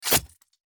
attack_hit_1.mp3